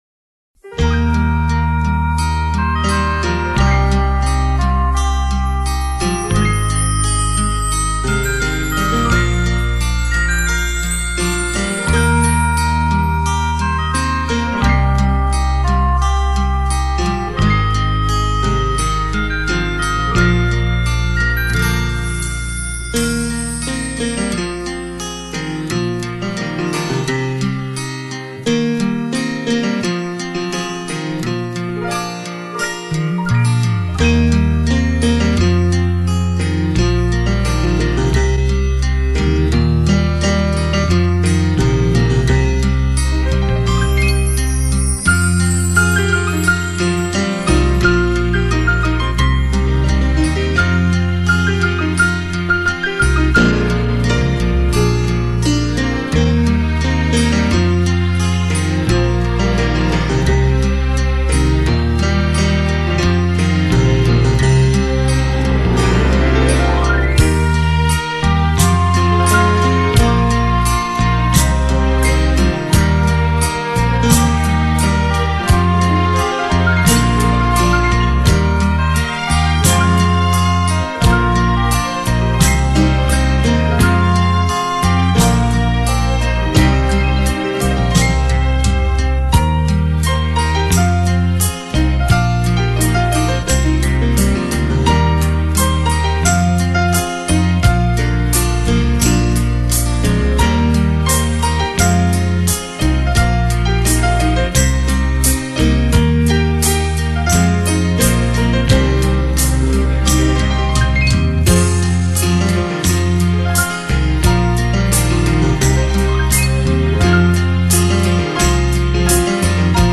黑胶3D音效
品位完美无暇的音乐，唯美典雅的钢琴世界，
给人一种清透心扉的淡雅之美……